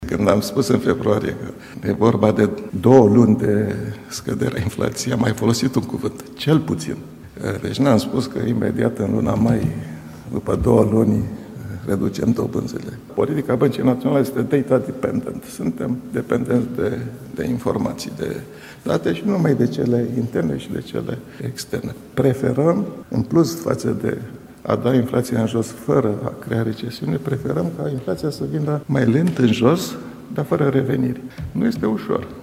Astăzi, acesta a prezentat raportul trimestrial asupra inflației și a spus că banca centrală va încerca să coboare încet inflația pentru a nu brusca economia.
Mugur Isărescu, guvernatorul BNR: „Preferăm ca inflația să vină mai lent, în jos, dar fără reveniri”